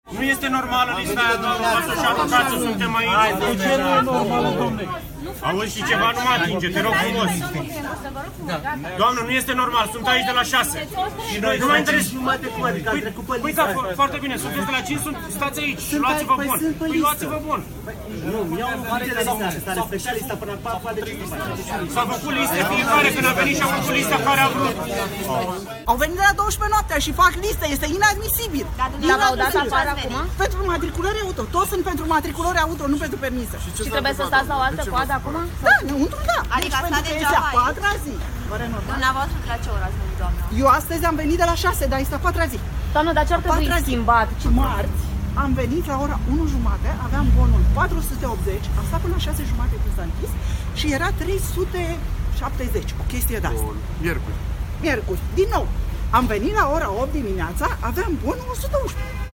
25nov-10-VOX-coada-la-permise-LUNG.mp3